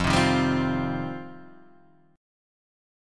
F7sus2 chord {1 3 1 0 1 1} chord